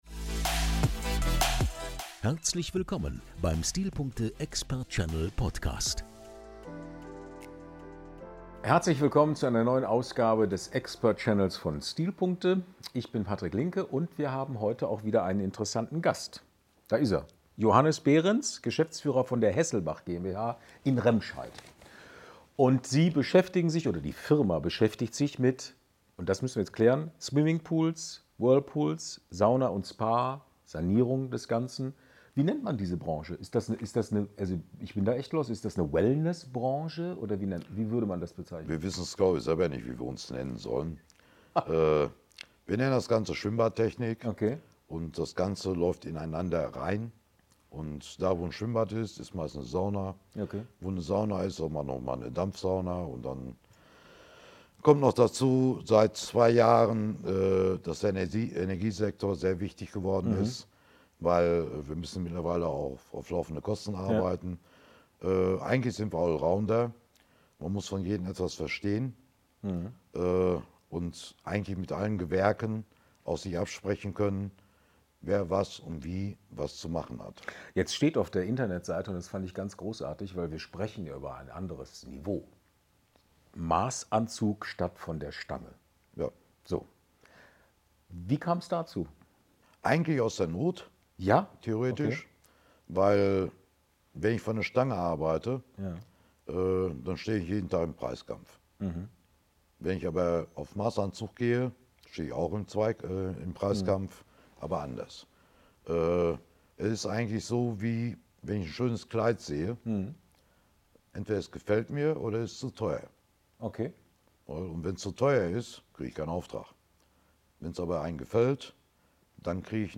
Der STILPUNKTE EXPERT CHANNEL bietet Ihnen faszinierende Einblicke und ausführliche Interviews mit führenden Experten und innovativen Unternehmern aus verschiedenen Branchen.